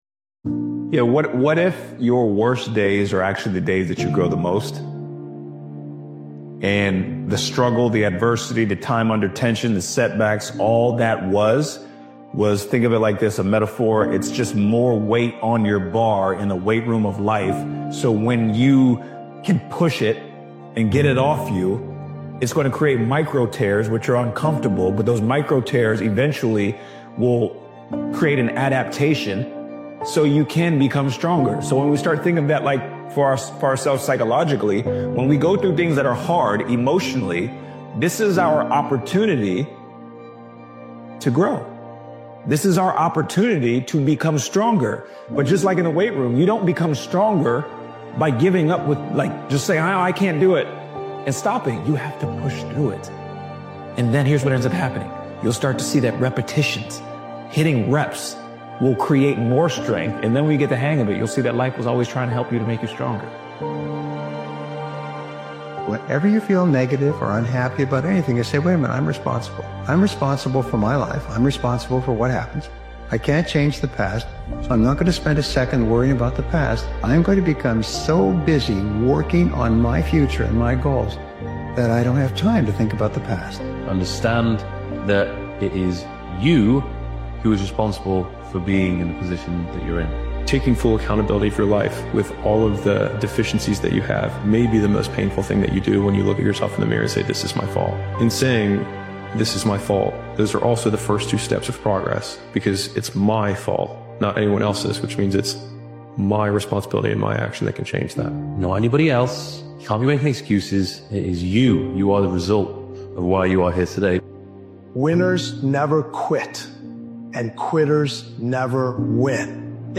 Powerful Motivational Speech is a raw and determined motivational speech created and edited by Daily Motivations. This powerful motivational speeches compilation is about drawing a line between who you were and who you’re becoming.